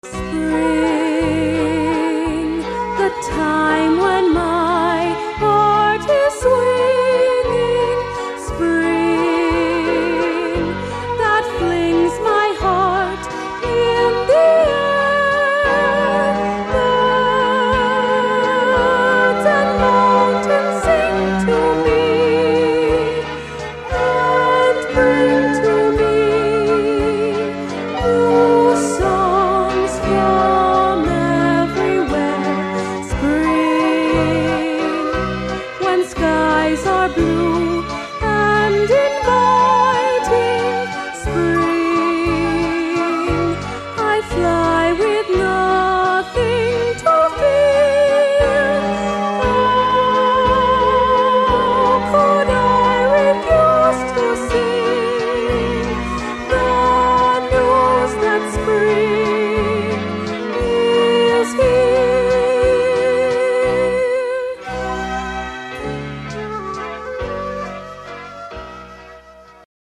Seoul Olympics Arts Festival '88